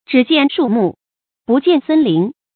zhǐ jiàn shù mù，bù jiàn sēn lín
只见树木，不见森林发音